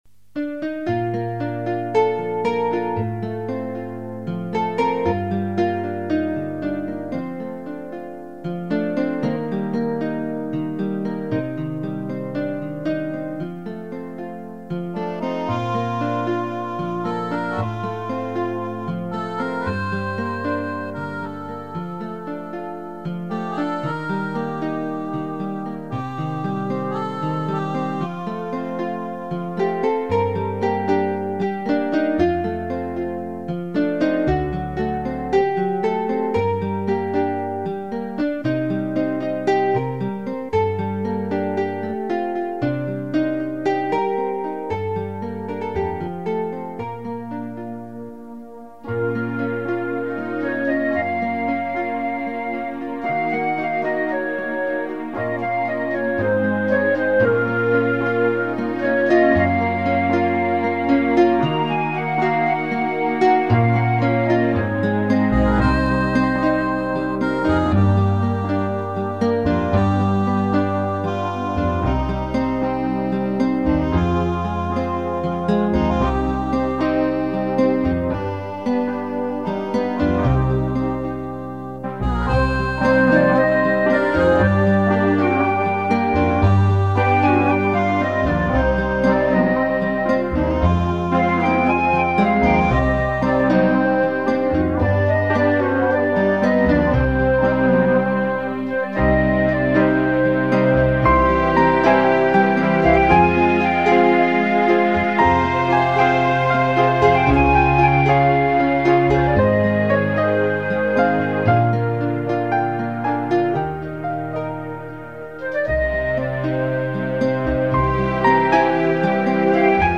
Lugn pianomusik.